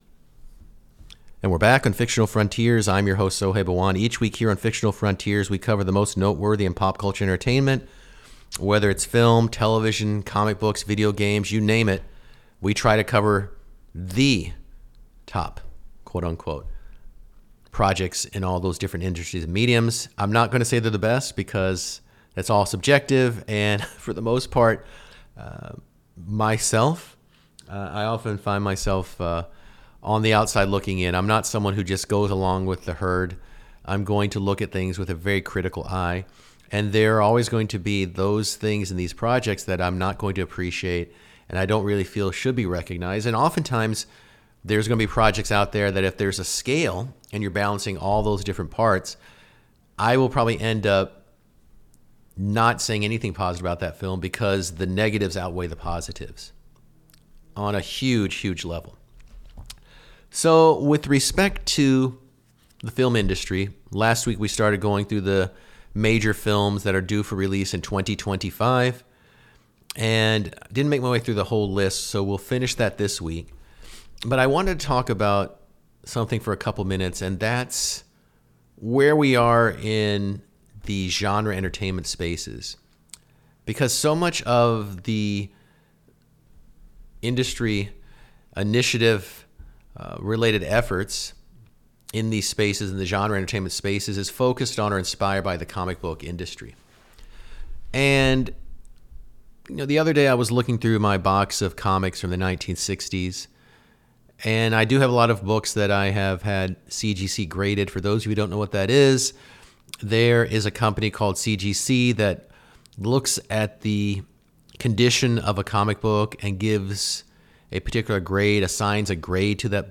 weekly radio program dedicated to a serious discussion of and about popular culture